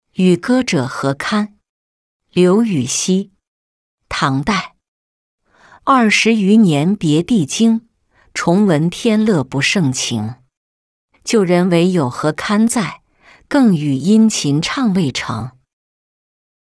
与歌者何戡-音频朗读